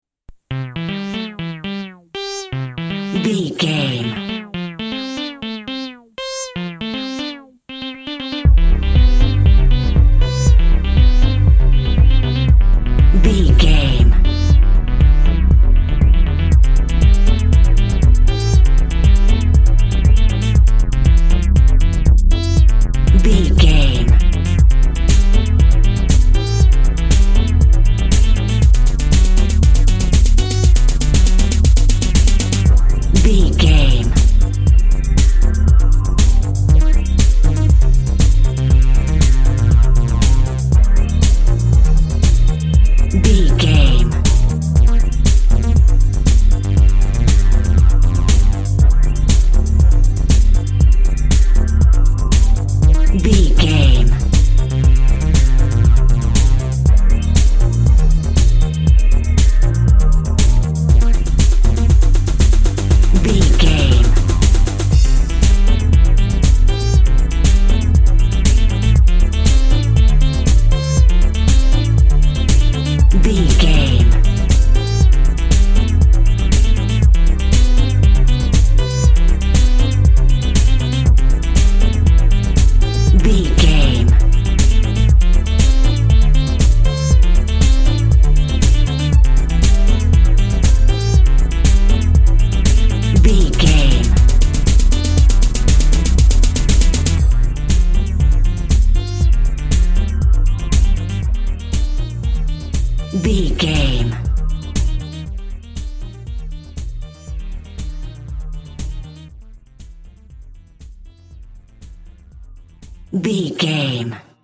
Nature Meets Electronic Music.
Ionian/Major
joyful
cheerful/happy
energetic
synthesiser
drum machine
techno
trance
synth lead
synth bass
Synth Pads